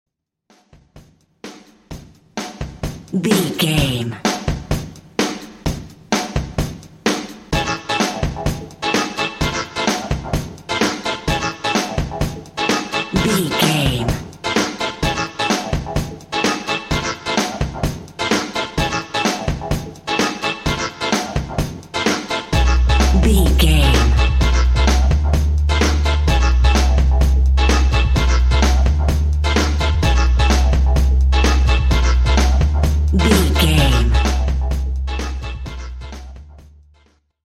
Atonal
groovy
energetic
uplifting
futuristic
hypnotic
industrial
drums
synthesiser
bass guitar
Funk
funky house
disco
synth lead
synth bass